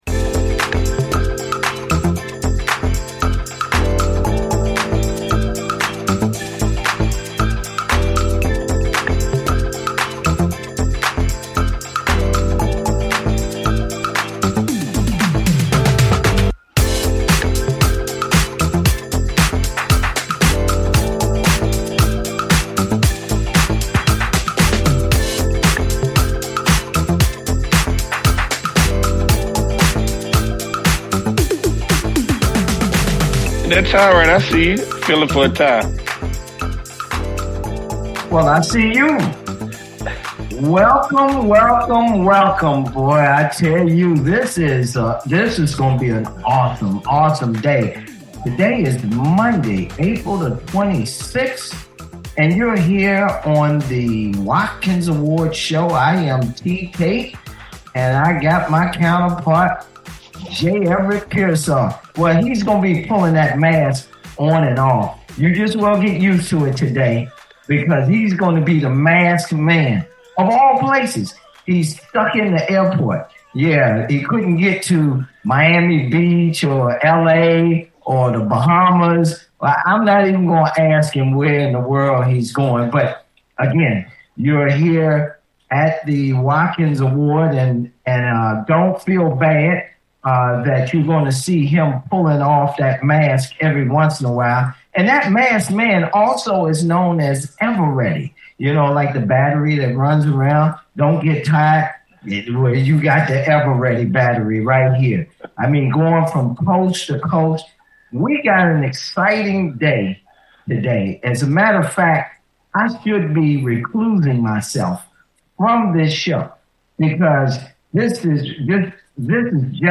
Guest, Robert Joshua Dobbs, is an American football quarterback for the Pittsburgh Steelers